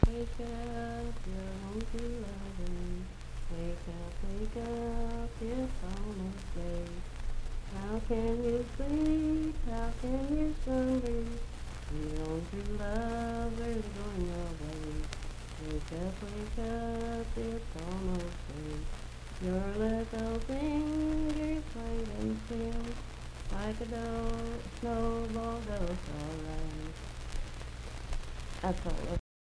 Unaccompanied vocal music
Verse-refrain 2(3-4).
Voice (sung)
Hardy County (W. Va.)